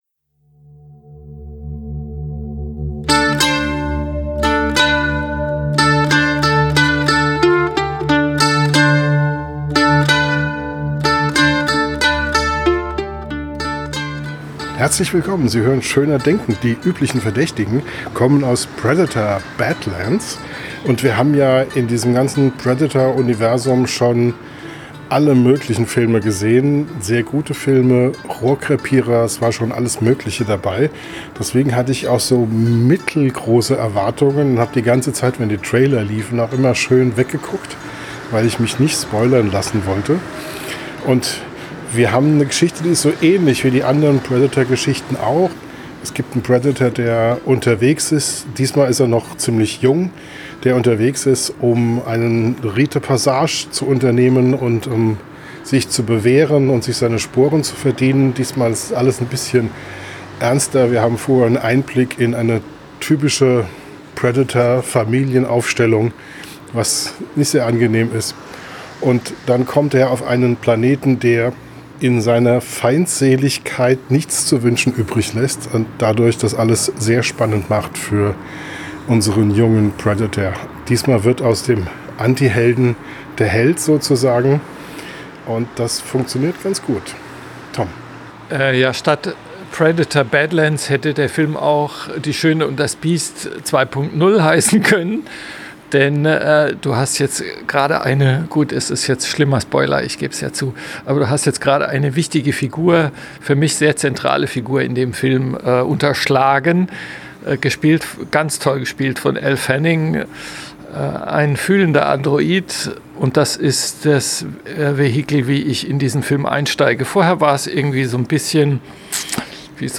Der erste Eindruck direkt nach dem Kino
Am Mikrofon direkt nach dem Kino: